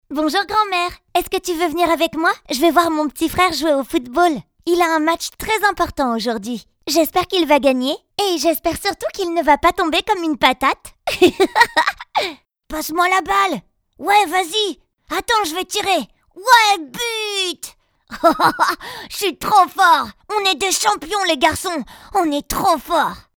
Sprechprobe: Sonstiges (Muttersprache):
I adapt my voice for each characters and each project : i can do voice like: little girl and little boy, a teenager, a yound adult, an adult and a grand ma.